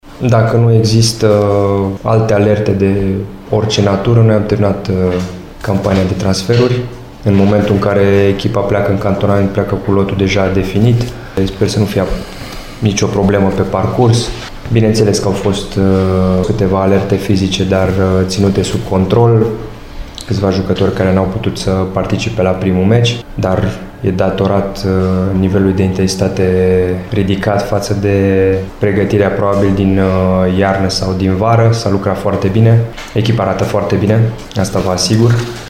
Keseru a mai precizat că lotul este „deja definit”:
8-iul-8.30-Keseru-am-cam-terminat-transferurile.mp3